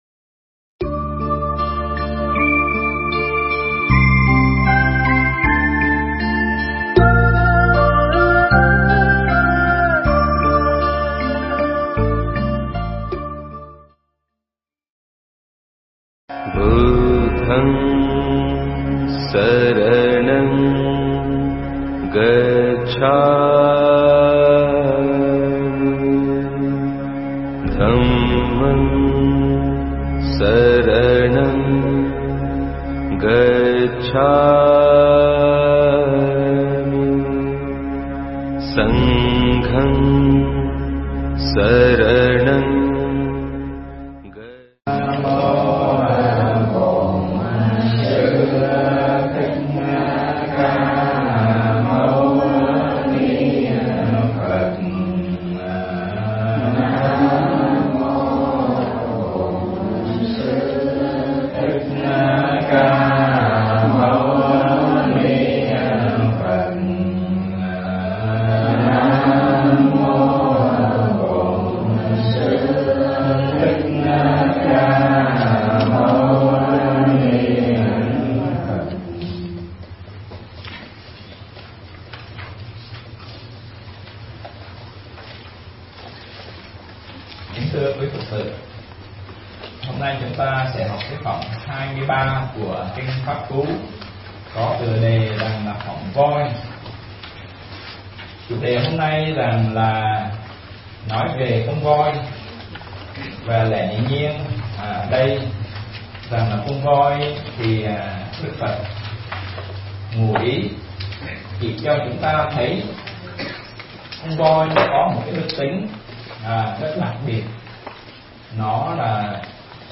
Nghe Mp3 thuyết pháp Kinh Pháp Cú Phẩm Voi